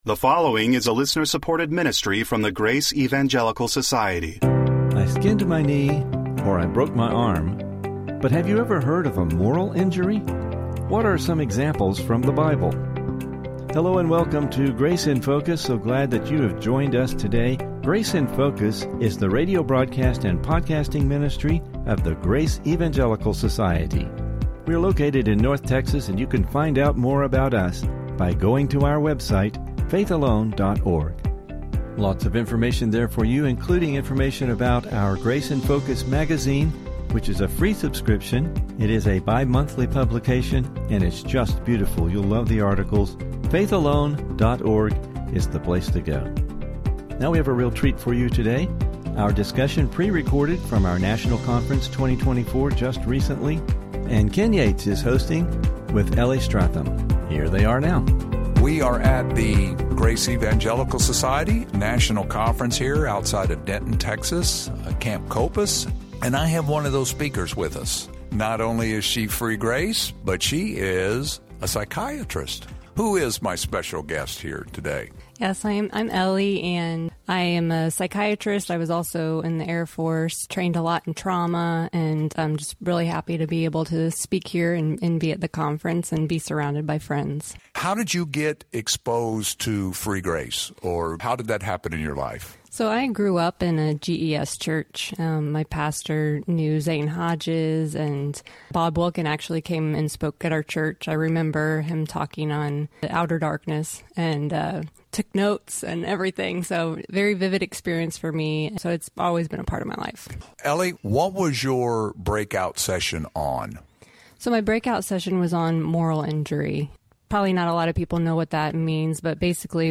What is the Biblical concept of forgiveness? Please listen for some interesting Biblical discussion regarding this topic!